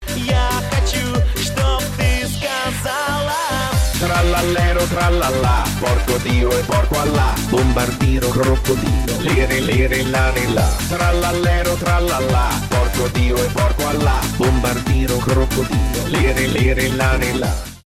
mashup , ремиксы